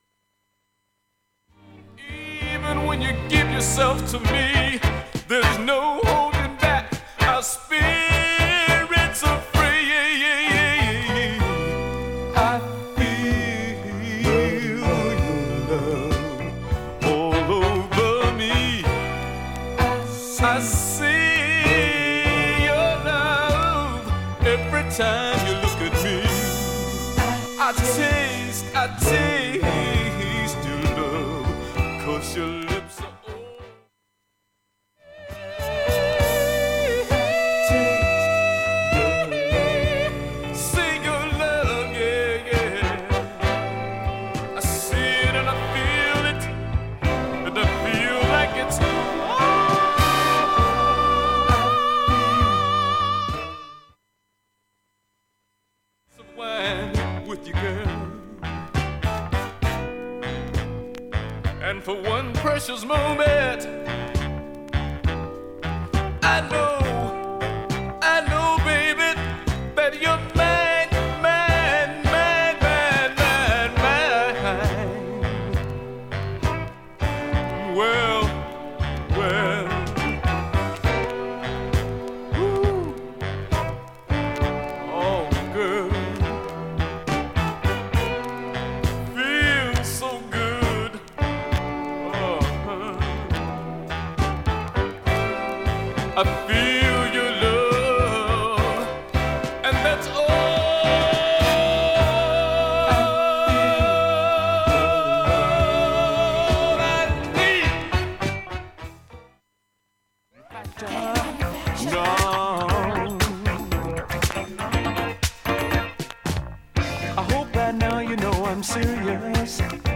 音質良好全曲試聴済み。
バックチリがわずかですが出ています。
A-3中盤に４回のわずかなプツが出ます。
現物の試聴（上記録音時間2分）できます。音質目安にどうぞ